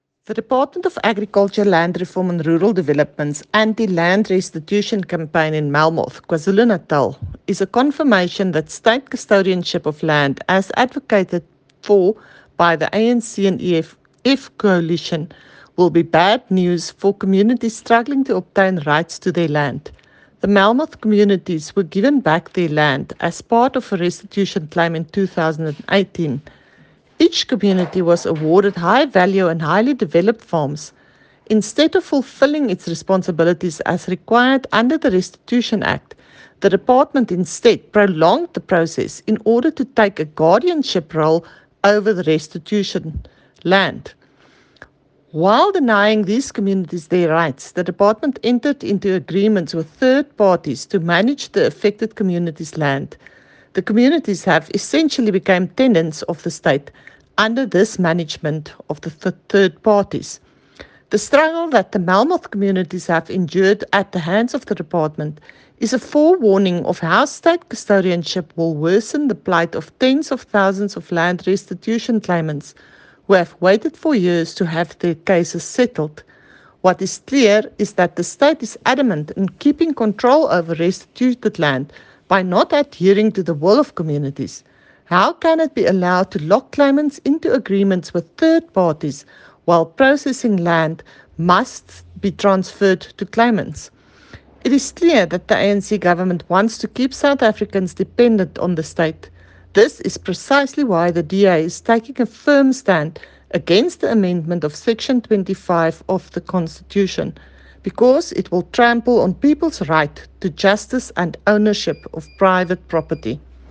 soundbite by Annette Steyn MP